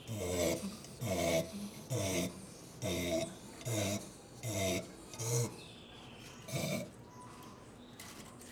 Skull of a mountain paca (Cuniculus taczanowskii), illustrating its enlarged cheek bones (at red arrow), which house resonating chambers for its vocalizations.
growl,
growl.wav